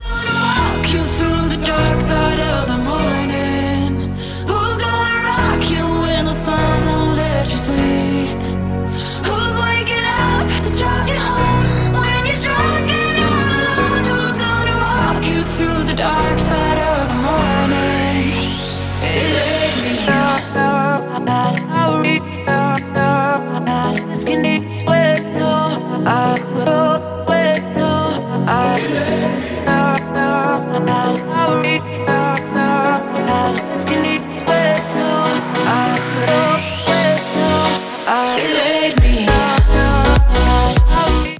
• Качество: 128, Stereo
женский вокал
deep house
dance
Electronic